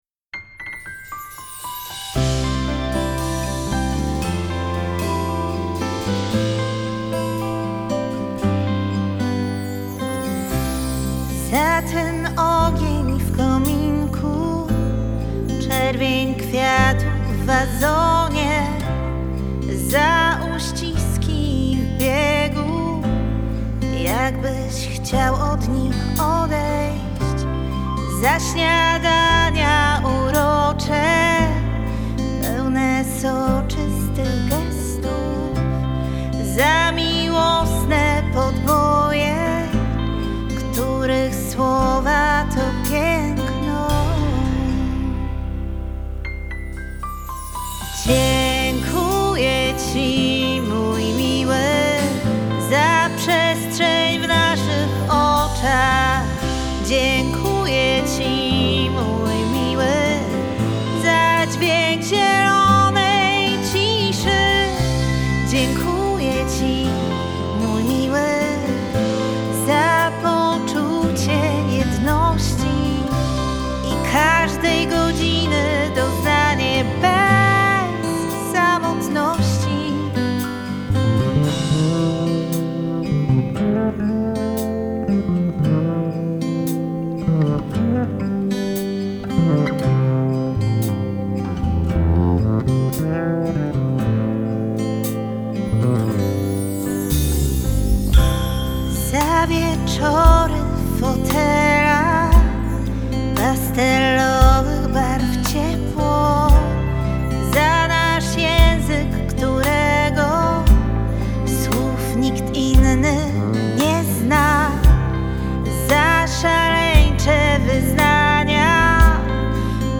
świątecznym, emocjonalnym i nastrojowym singlu